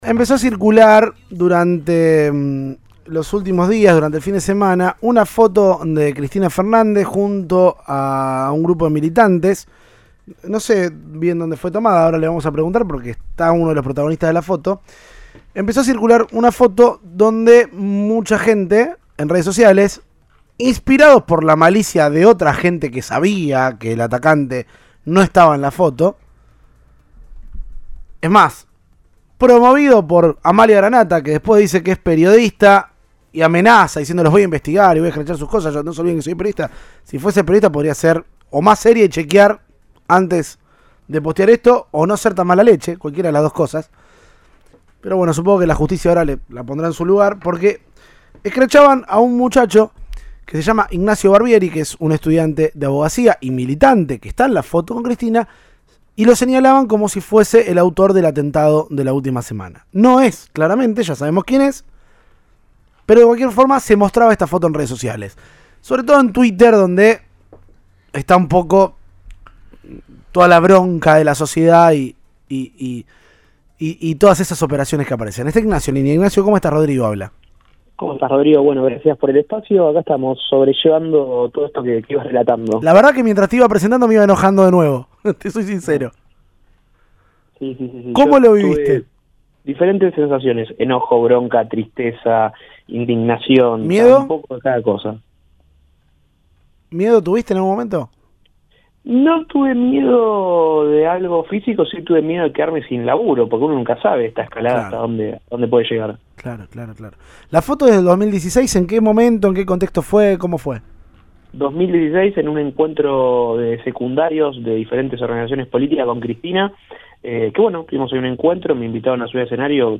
Su testimonio en Cadena 3 Rosario.